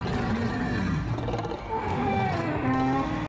PrimigeniusHonjuroar.mp3